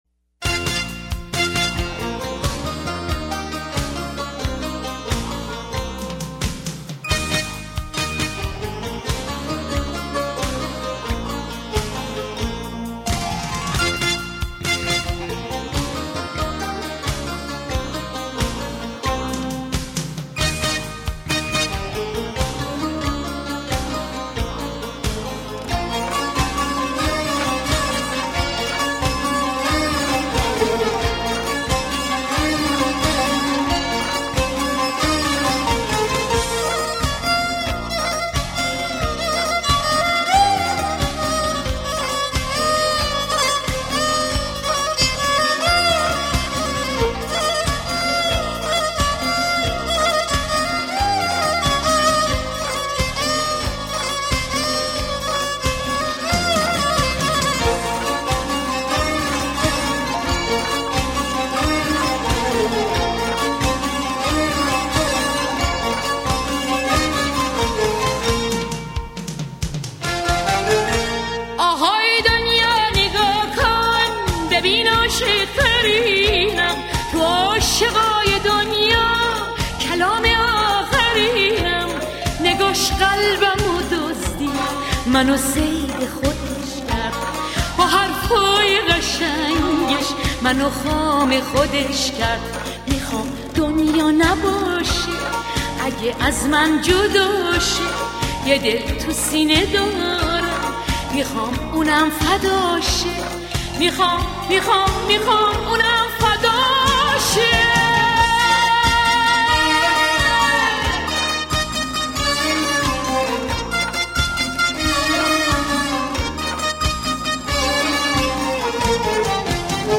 اهنگ شاد ایرانی اهنگ شاد قدیمی